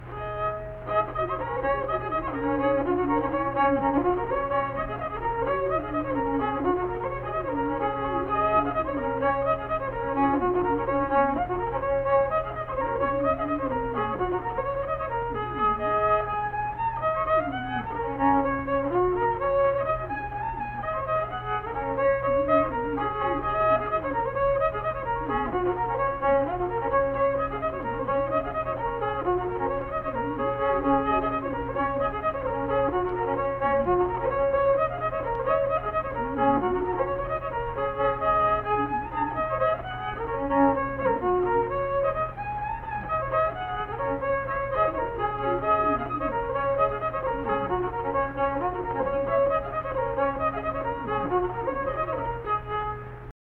Unaccompanied fiddle music performance
Verse-refrain 3(2).
Instrumental Music
Fiddle